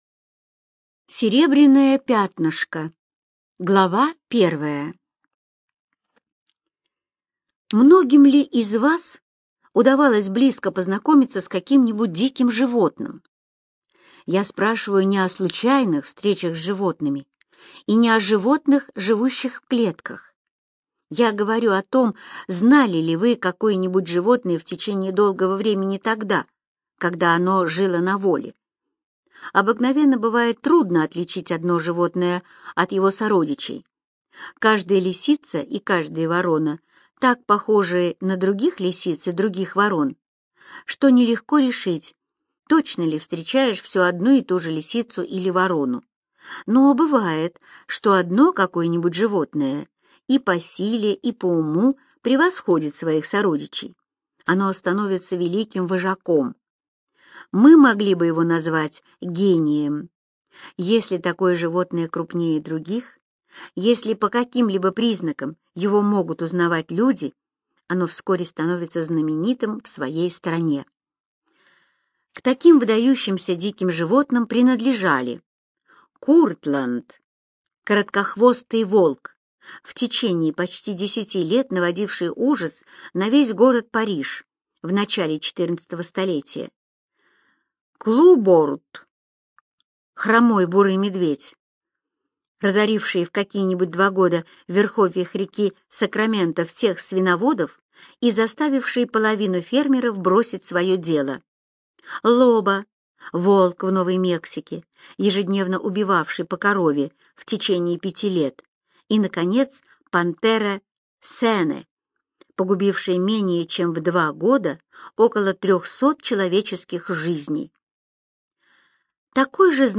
Серебряное Пятнышко - аудио рассказ Эрнеста Сетона-Томпсона - слушать онлайн